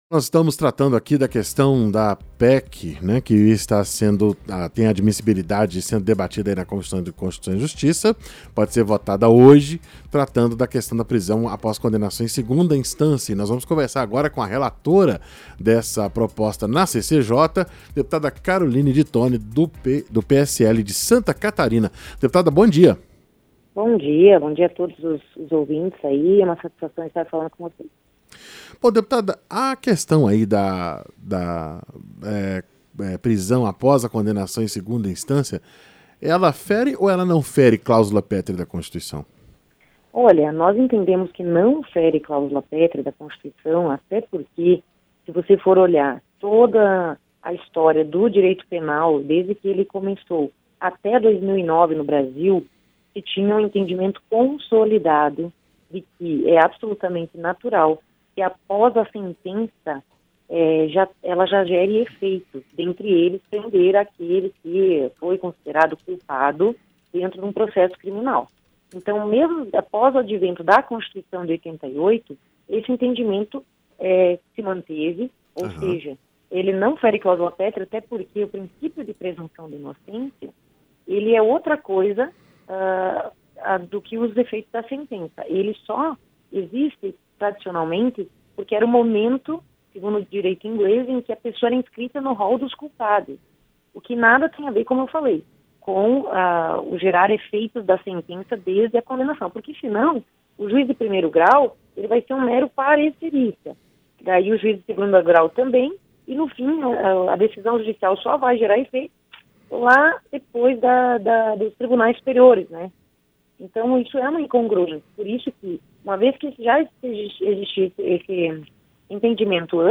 Entrevista - Dep. Caroline de Toni (PSL-SC)